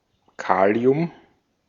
Ääntäminen
Ääntäminen Tuntematon aksentti: IPA: /pɔ.ta.sjɔm/ Haettu sana löytyi näillä lähdekielillä: ranska Käännös Ääninäyte Substantiivit 1.